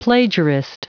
Prononciation du mot plagiarist en anglais (fichier audio)
plagiarist.wav